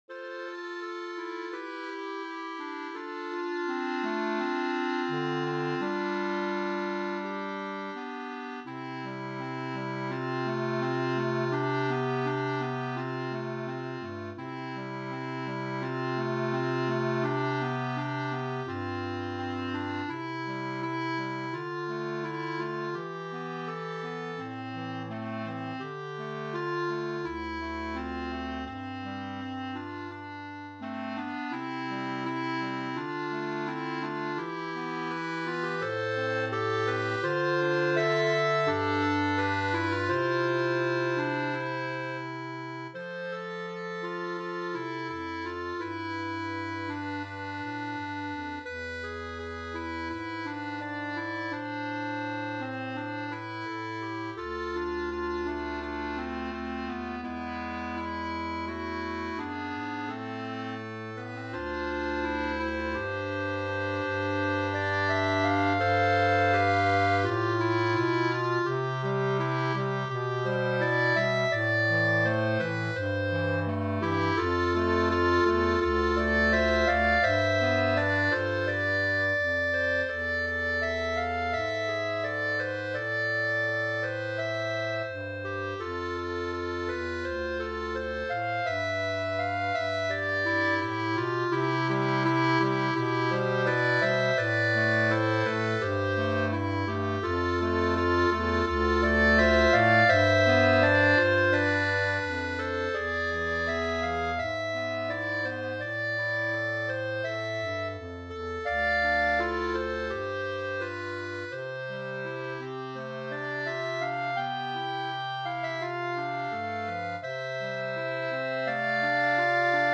B♭ Clarinet 1 B♭ Clarinet 2 B♭ Clarinet 3 Bass Clarinet
单簧管四重奏
风格： 流行